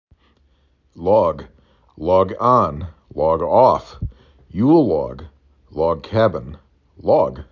3 Letters, 1 Syllable
l aw g